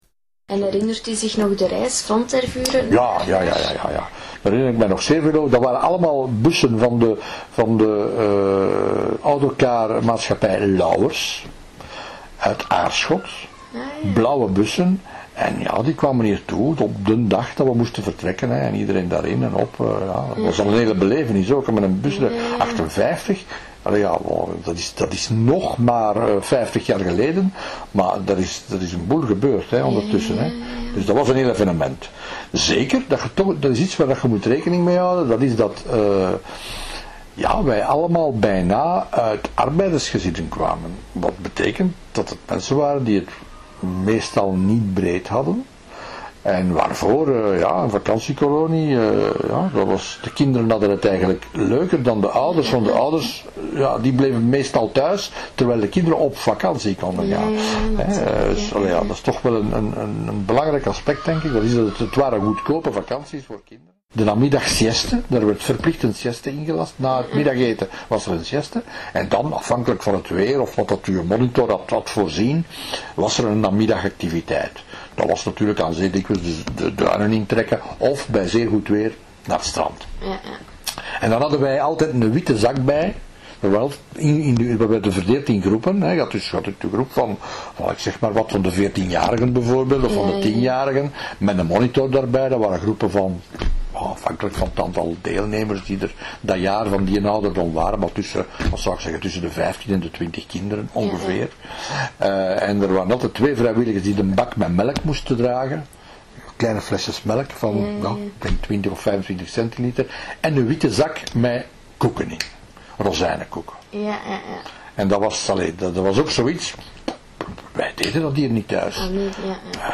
Getuigenissen